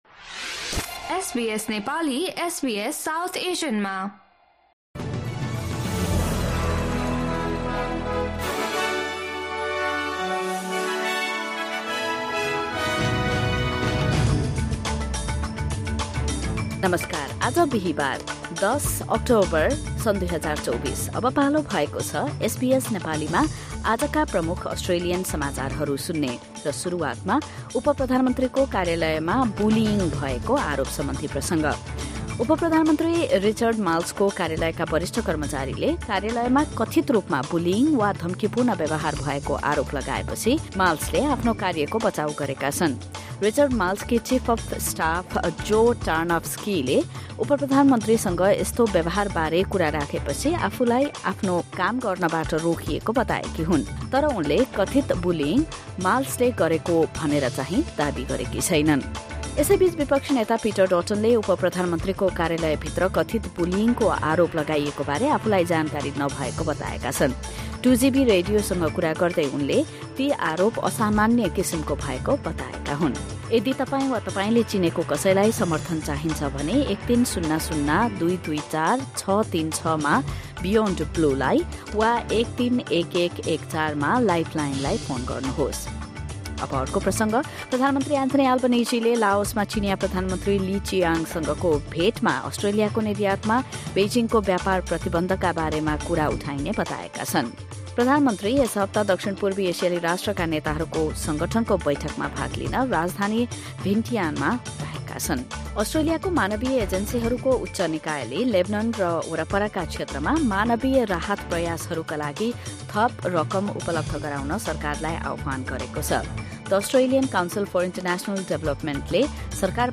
SBS Nepali Australian News Headlines: Thursday, 10 October 2024